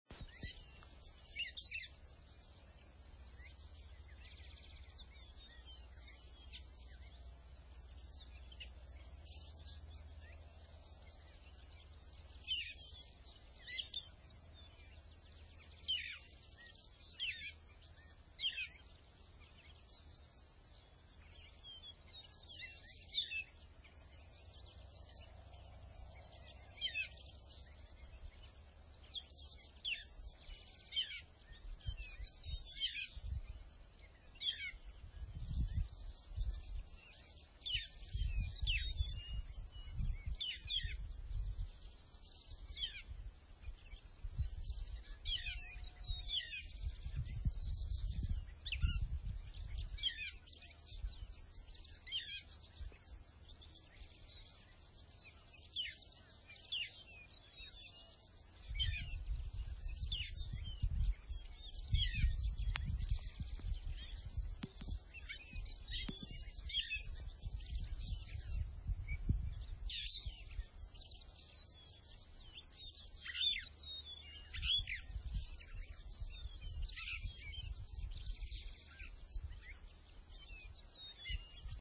Звуки кошачьего пересмешника
Их песни состоят из случайных, но повторяющихся нот, включающих имитации других видов и неожиданные звуки.
Взрослая особь поет издалека